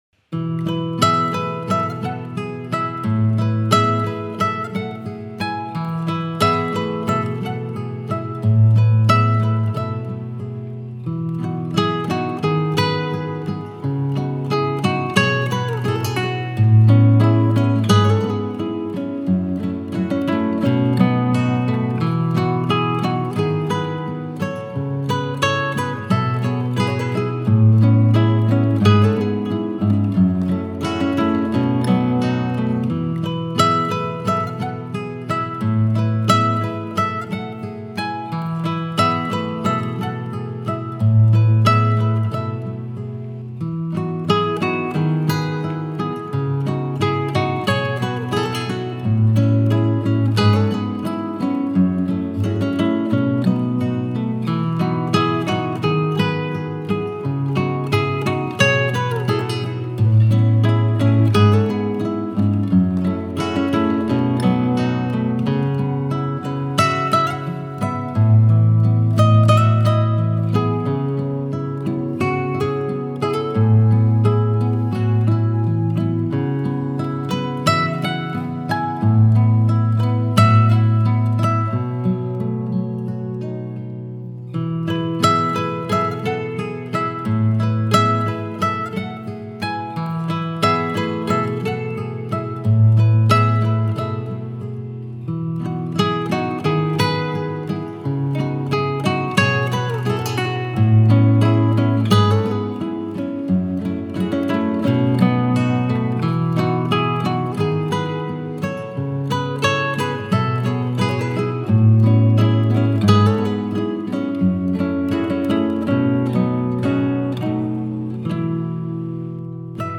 آرامش بخش , گیتار , موسیقی بی کلام